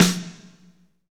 Index of /90_sSampleCDs/Northstar - Drumscapes Roland/DRM_AC Lite Jazz/SNR_A_C Snares x